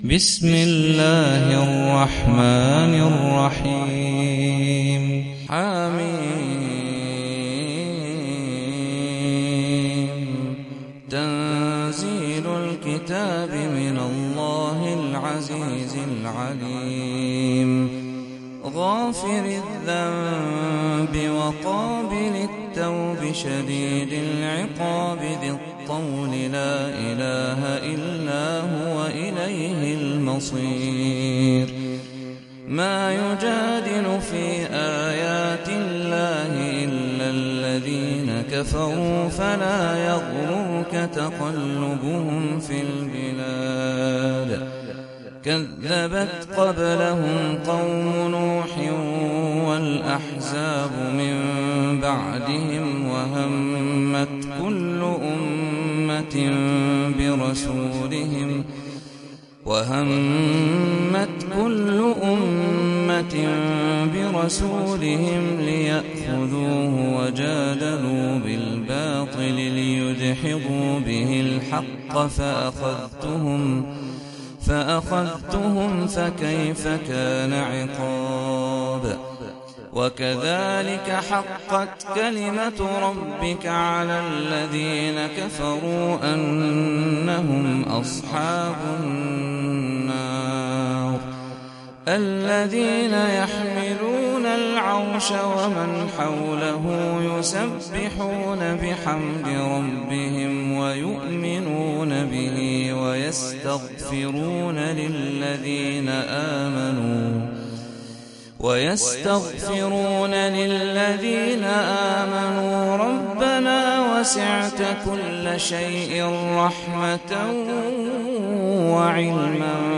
Sûrat Ghafir (The Forgiver God) - صلاة التراويح 1446 هـ (Narrated by Hafs from 'Aasem